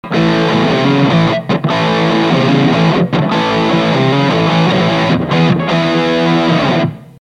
Guitar Fender STRTOCASTER
Amplifier VOX AD30VT AC15
GAIN全開VOLUME全開
違いの分かりやすい「アリキックポジション（低域増幅）」での